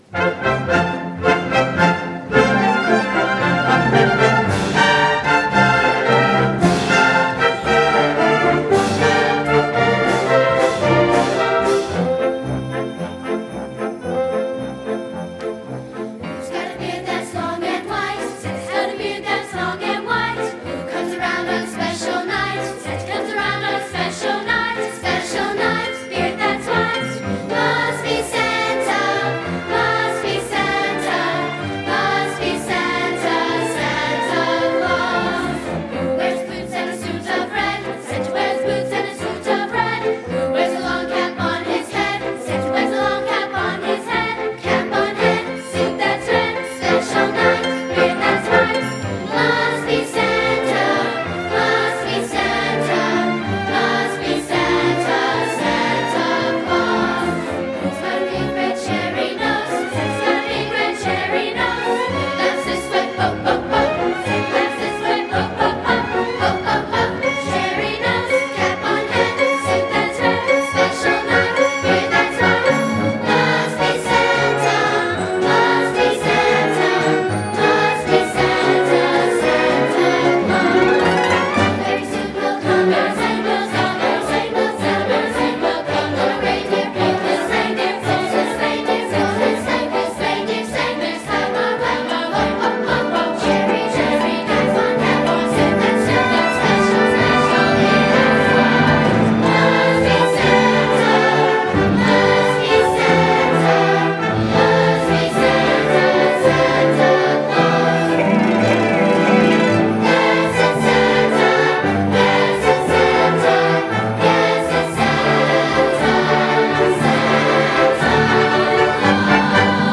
Theme: Christmas
Ensemble: Children's Chorus